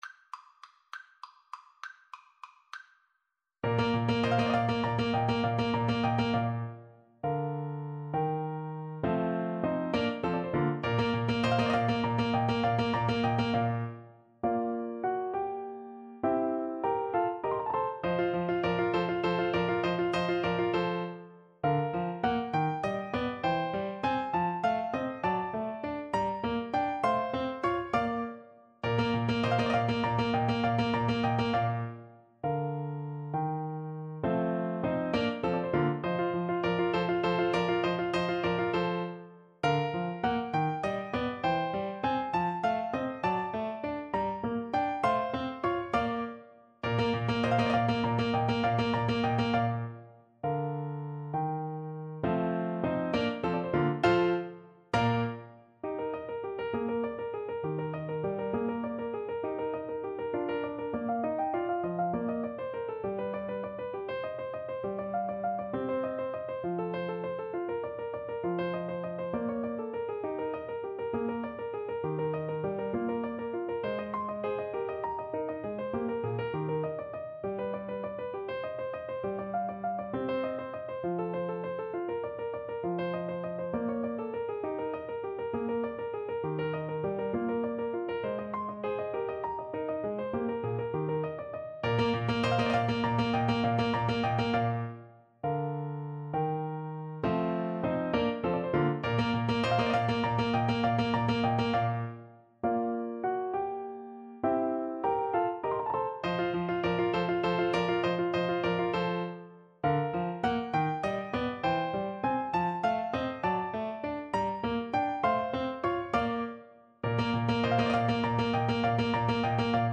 3/4 (View more 3/4 Music)
Allegro vivace =200 (View more music marked Allegro)
Classical (View more Classical Violin Music)